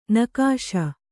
♪ nakāśa